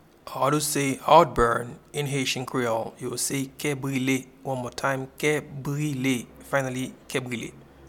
Pronunciation and Transcript:
Heartburn-in-Haitian-Creole-Ke-brile.mp3